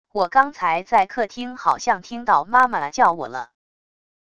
我刚才在客厅好像听到妈妈叫我了wav音频生成系统WAV Audio Player